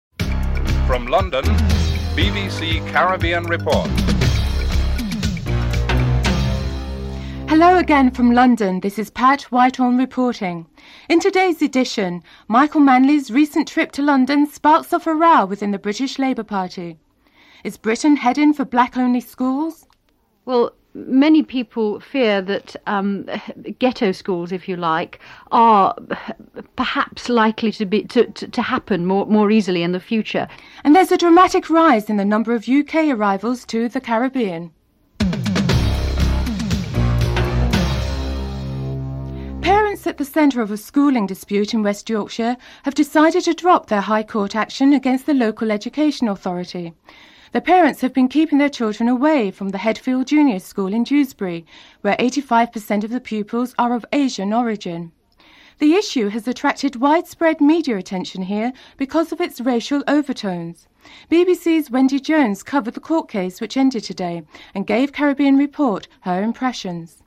This program contains news coverage related to the Caribbean as well as Great Britain. the foci of atttention in this broadcast are the question as to whether Britain was headed towards black only or "ghetto" schools; the drmatic rise in the number of tourist arrivals in the Caribbean from the U.K.; and a row within the British Labour Party, sparked by the recent visit of Jamaican Prime Minister Michael Manley to London.
1. Headlines (00:07-00:38)